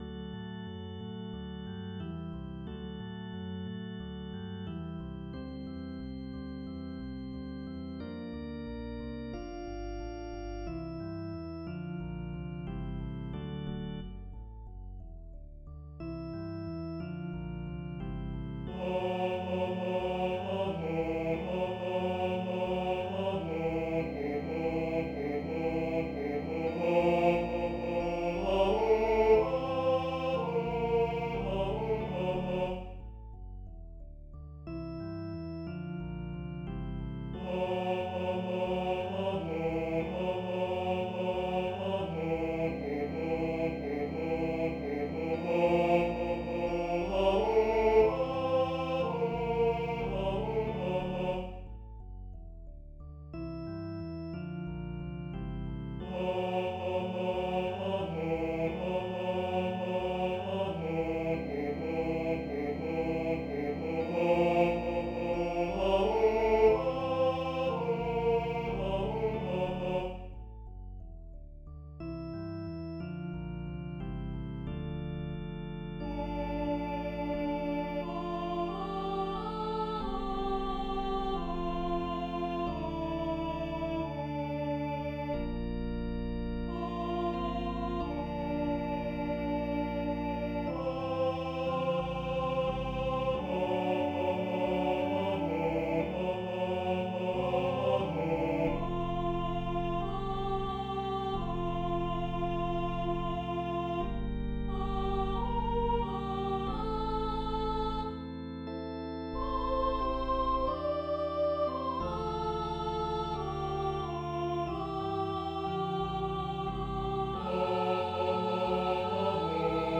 Organ/Organ Accompaniment, Trio
Voicing/Instrumentation: Organ/Organ Accompaniment , Trio
Christmas